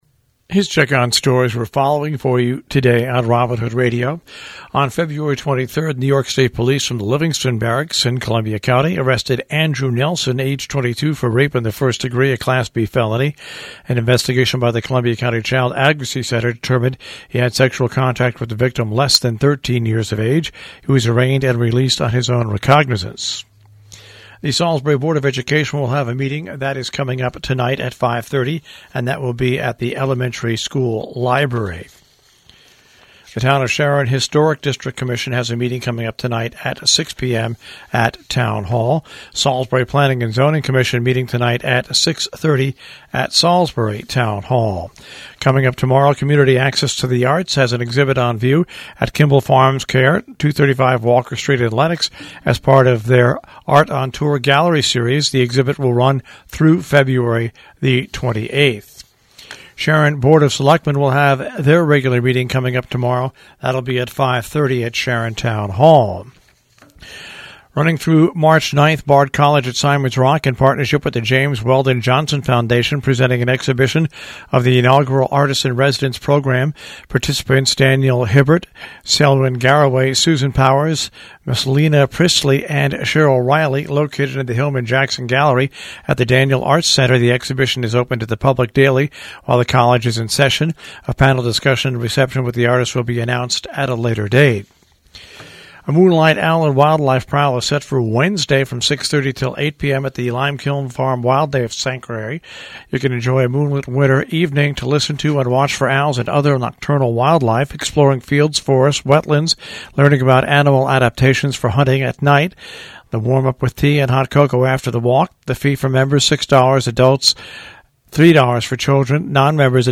WHDD-BREAKFAST CLUB NEWS MONDAY FEBRUARY 26.mp3